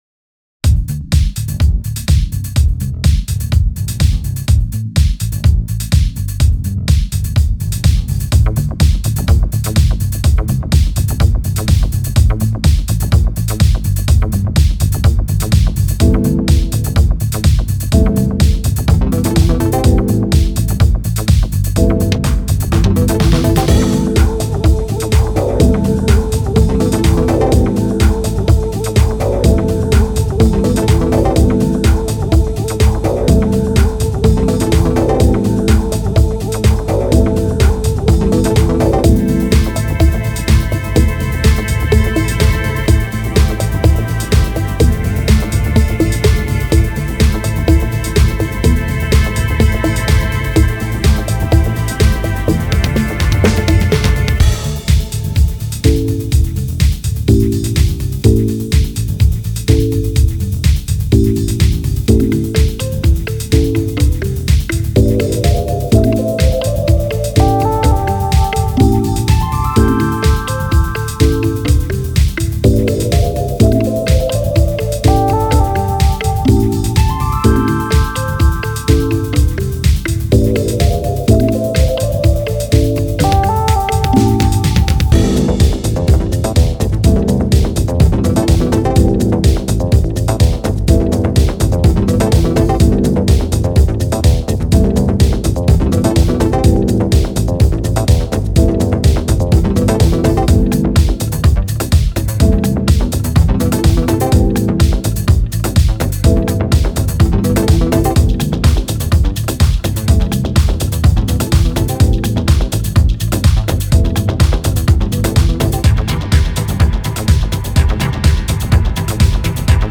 # Dance   # RnB    # Electronic    # Alternative Rock